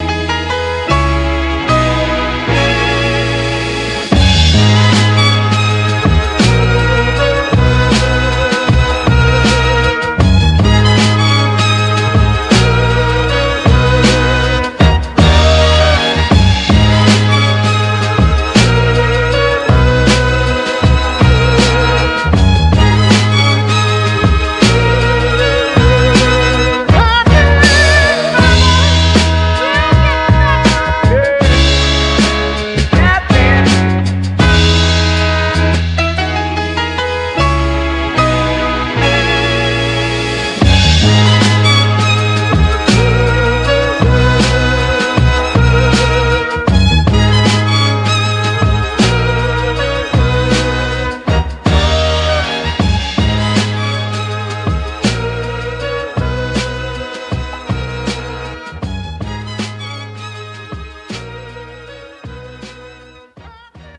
royalty-free sample pack